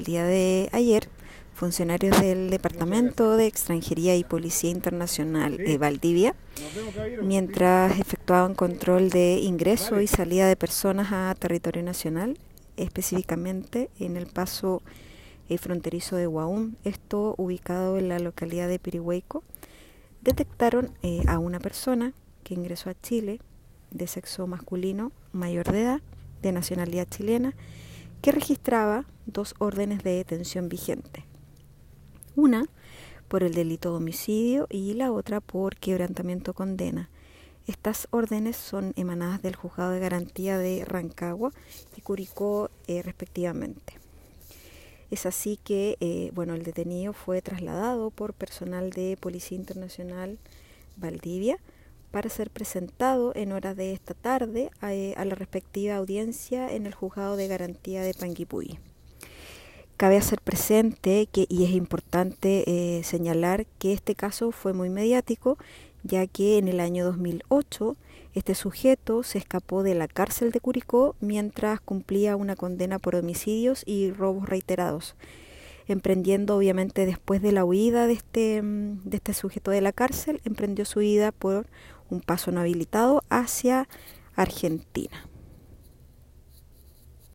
Cuña